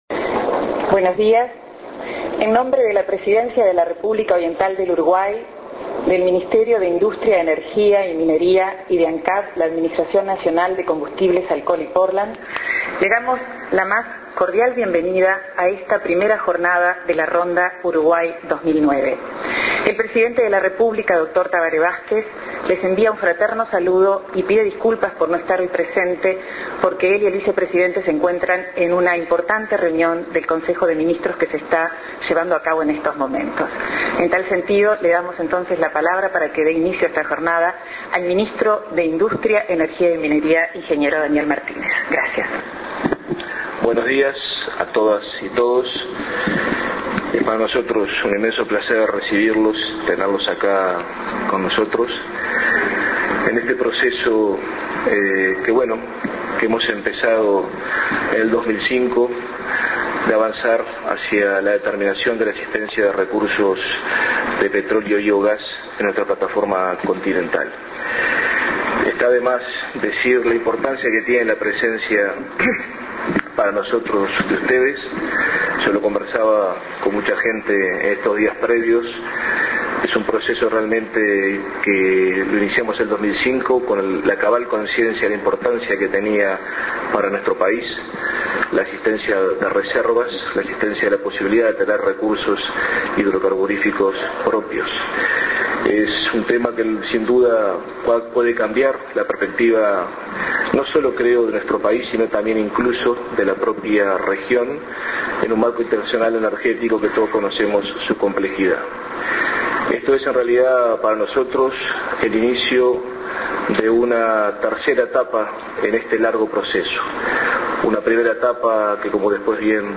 Palabras de Daniel Mart�nez Escuchar MP3 Ver video (WMV)